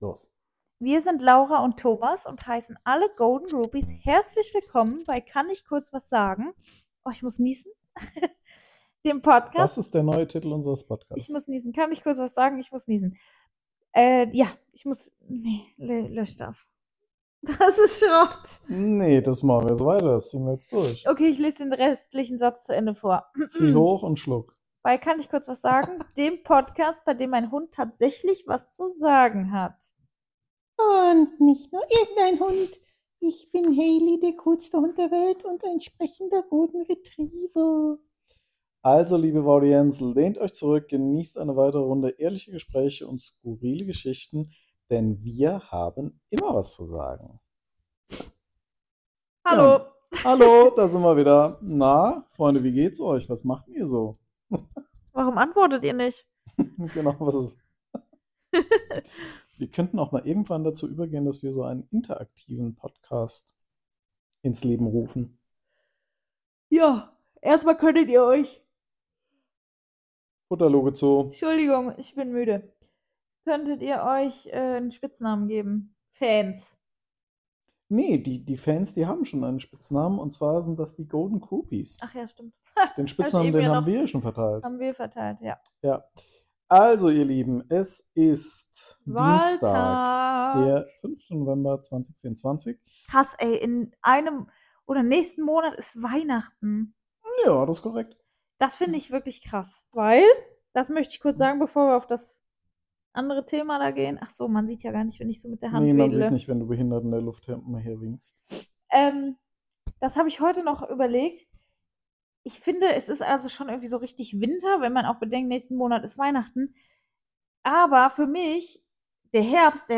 Dazu gibt’s unseren gewohnten, unverblümten Trash Talk – gnadenlos ehrlich und frei von allem, was nach „Kuschelkompromiss“ klingt.